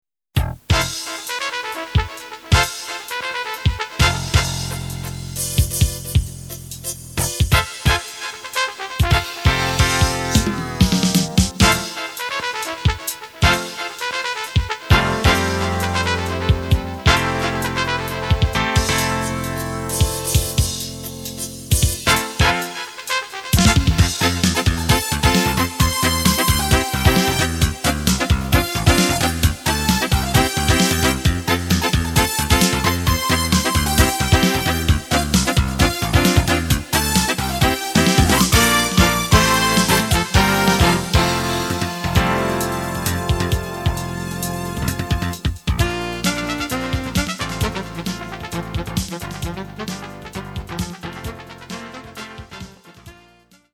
Writing of modern style Jazz.